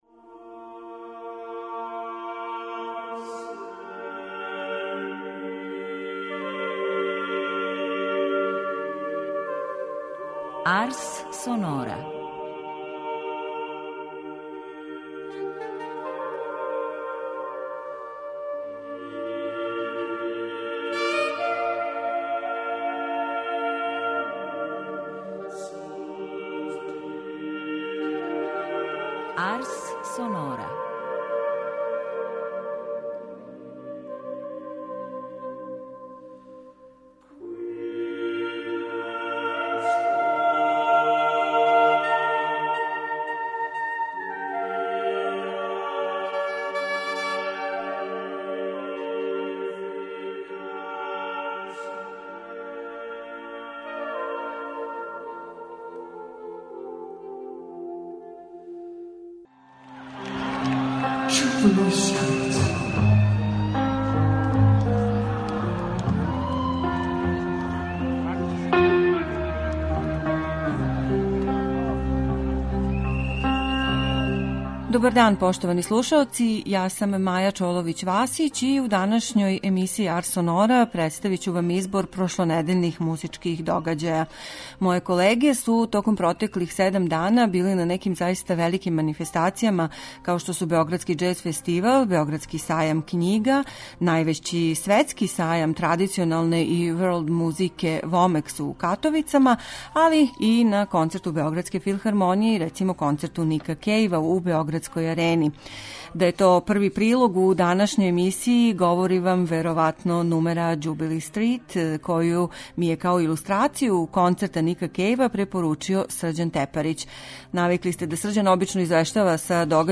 У данашњој Арс сонори ћете чути ексклузивну репортажу са WОМЕX-а, највећег светског сајма world музике који се ове године одржао у Катовицама, извештај са 33. Београдског џез фестивала, прилог о концерту Београдске филхармоније из циклуса Ватра , а чућете и шта се од нових музичких издања могло ове године пронаћи на 62. Београдском сајму књига.